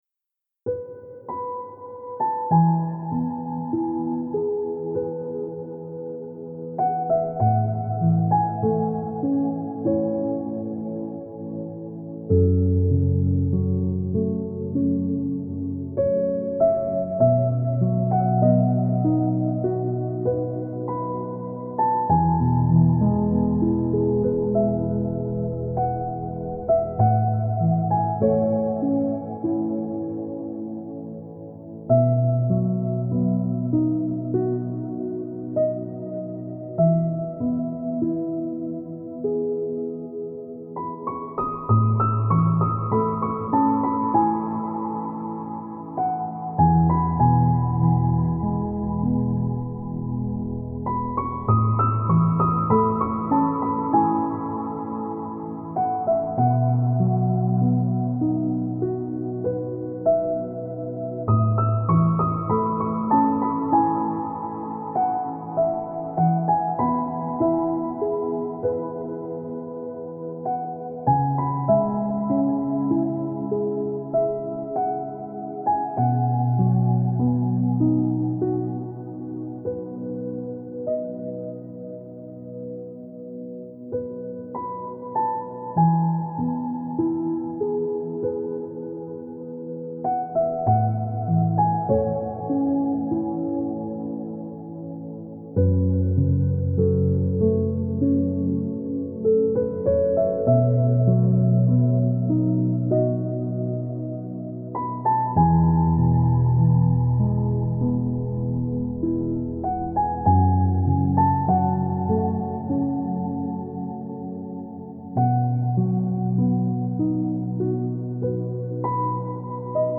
это вдохновляющая композиция в жанре поп